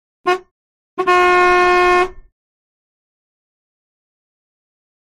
Horn Honk; Smooth timbred Horn, Very Short And Long Honks. Close Perspective.